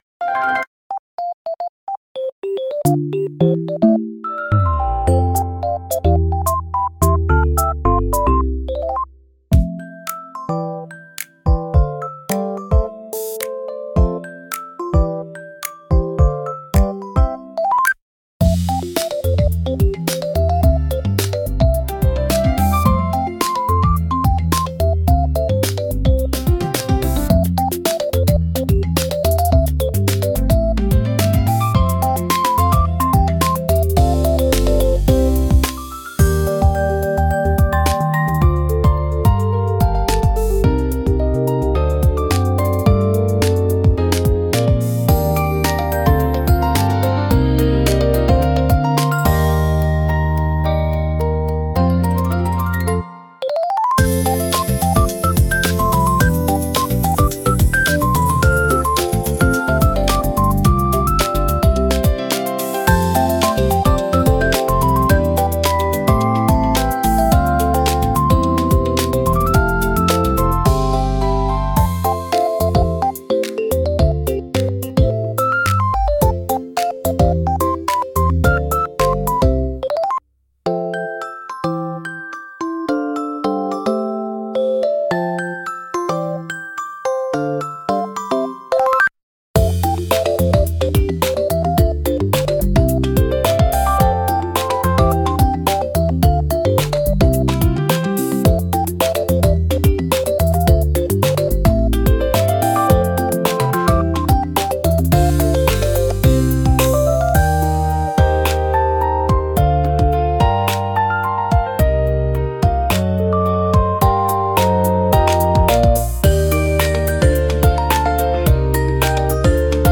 ゆめかわピコピコBGM